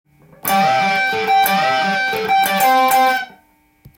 オリジナルギターtab譜　key Am
④のフレーズは、和音系ペンタトニックフレーズになります。